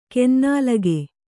♪ kennālage